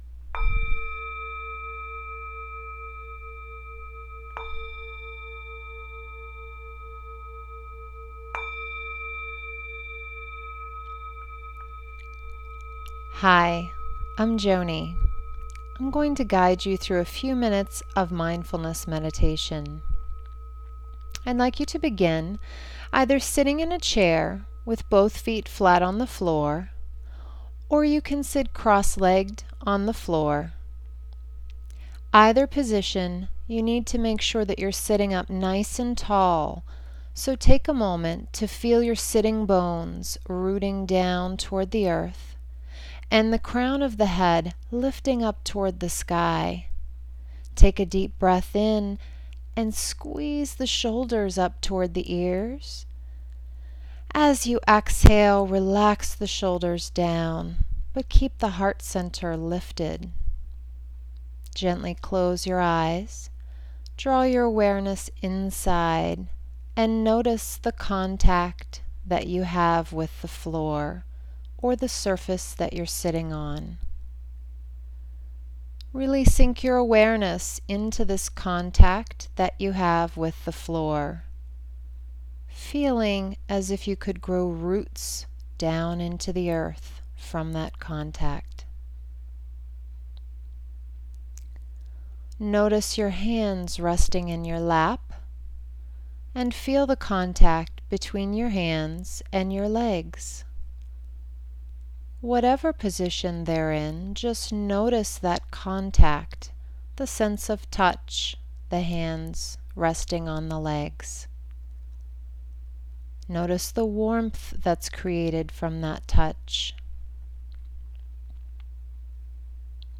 Updated 6 minute Guided Mindfulness Meditation
It was my first attempt with such technology, and so there were some issues with the sound/volume. I've re-recorded.